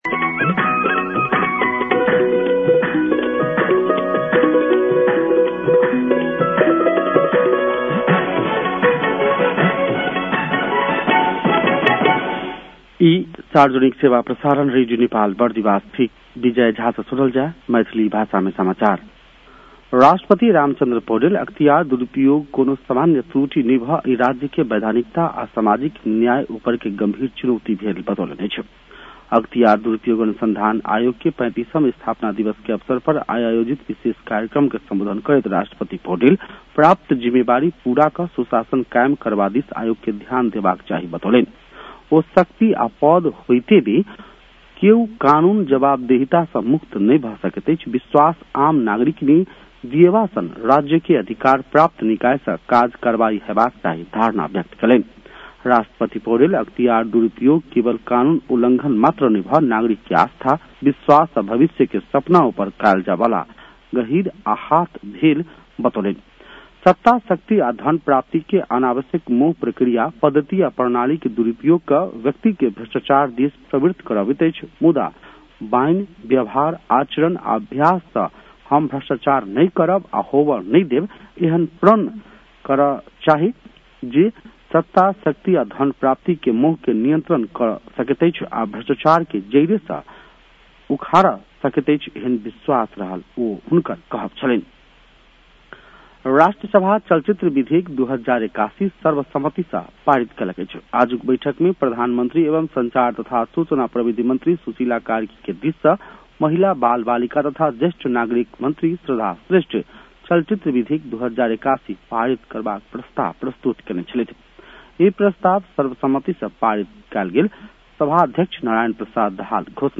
मैथिली भाषामा समाचार : २८ माघ , २०८२